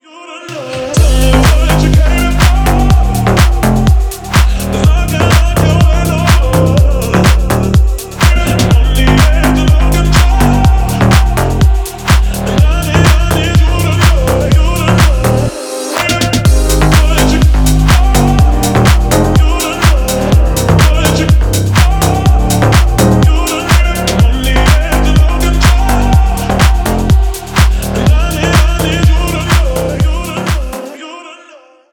• Качество: 320, Stereo
мужской голос
басы
Стиль: deep house